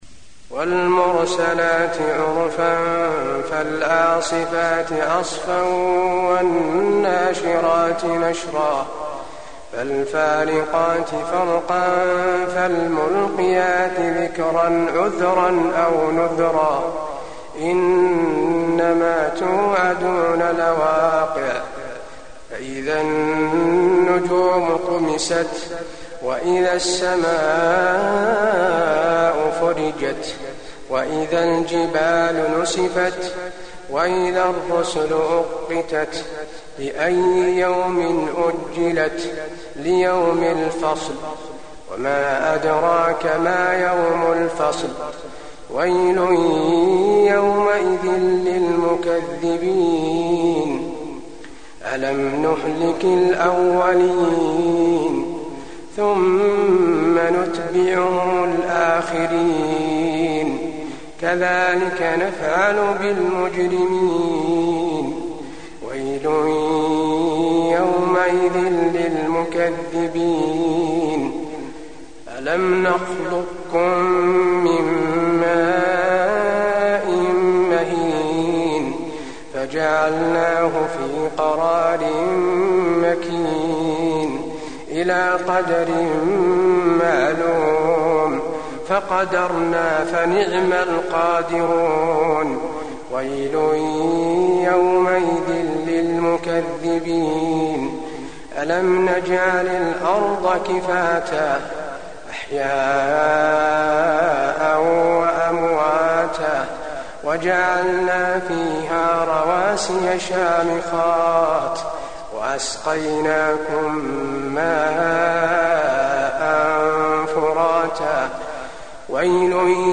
المكان: المسجد النبوي المرسلات